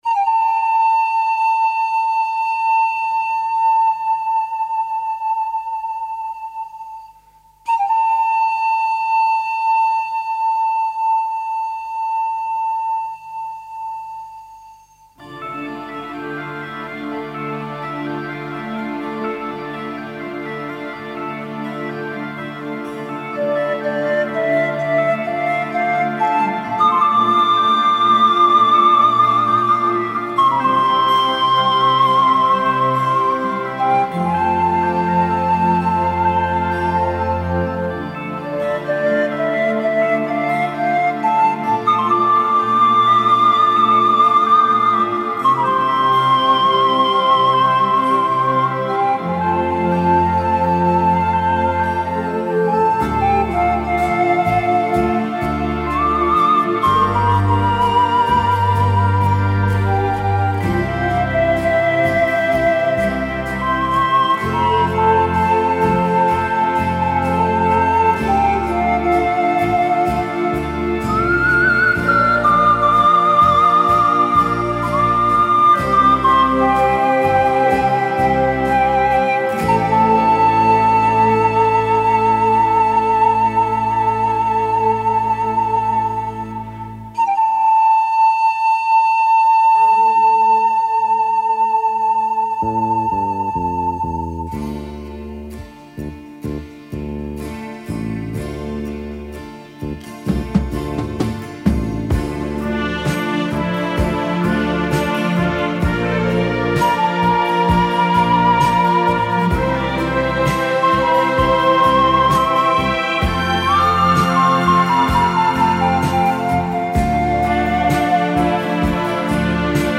Звучание этого инструмента завораживает и волнует ))))